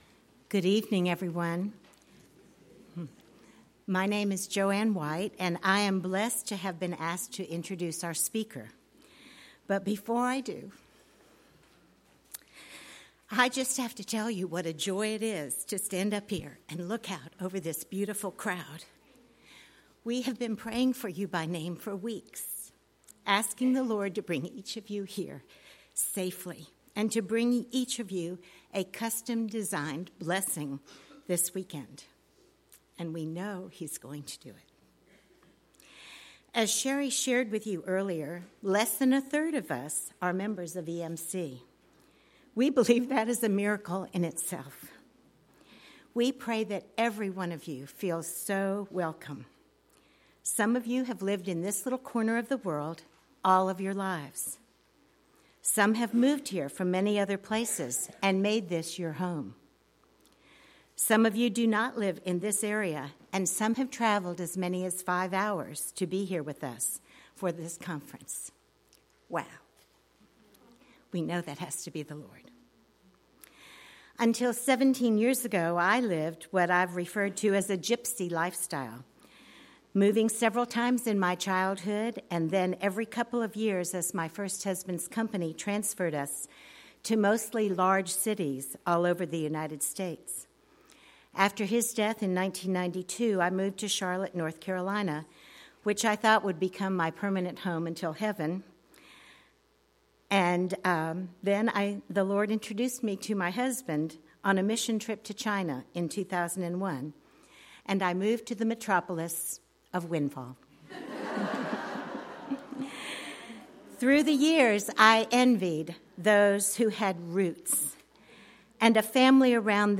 2019 Women’s Conference